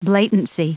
Prononciation du mot blatancy en anglais (fichier audio)
Prononciation du mot : blatancy